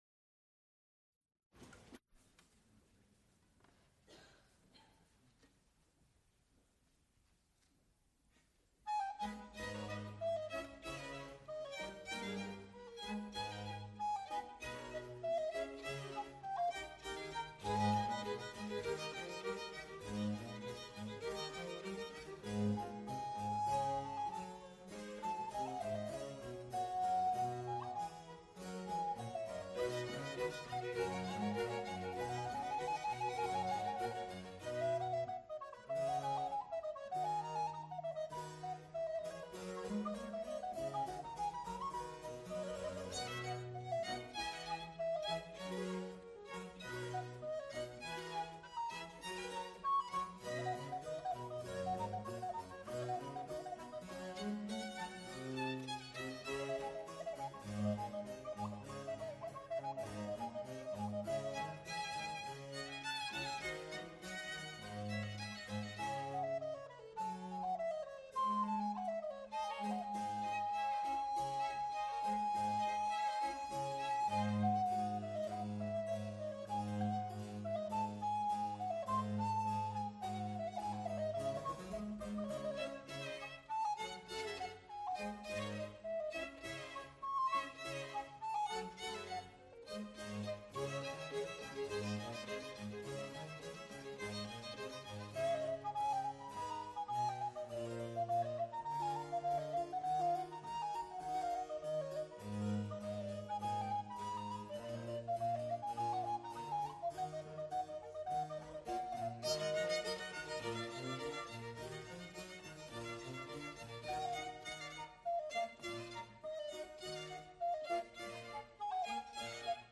Recorded live February 28, 1978, Frick Fine Arts Auditorium, University of Pittsburgh.
Genre musical performances
Concerti grossi